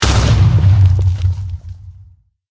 explode1.ogg